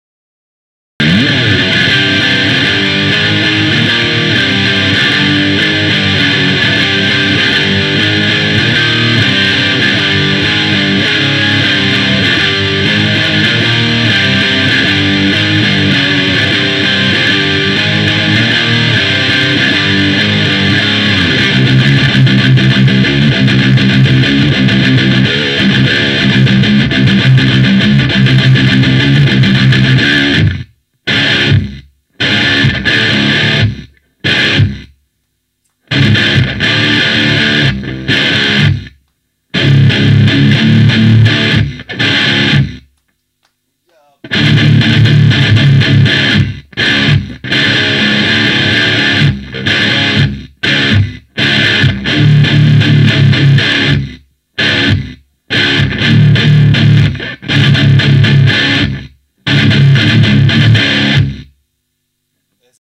To ensure the amp’s true voice shines through, recordings were made using a Shure SM57 mic on a Marshall cabinet, alongside a direct line from the amp’s balanced SM57 mic sim output.
We kept effects minimal to let the amp speak for itself — just a hint of reverb, light delay, and a short wah pedal section.”
Guitar2.m4a